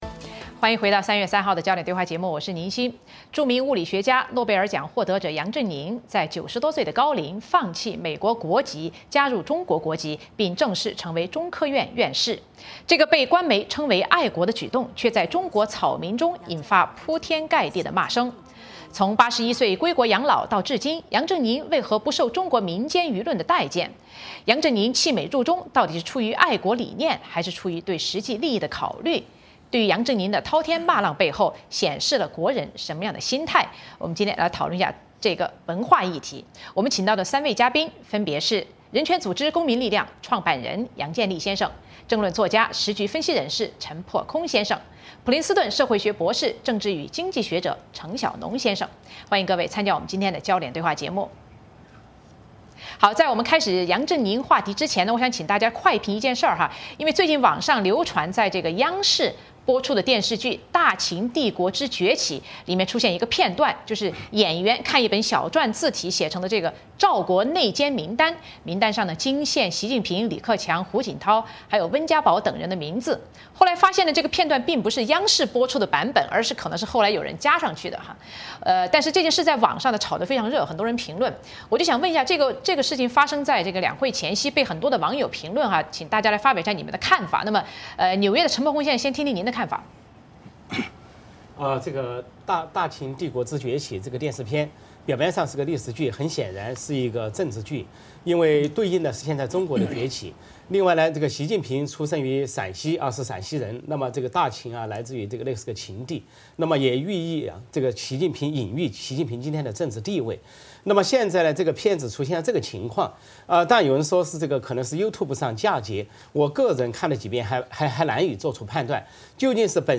讨论嘉宾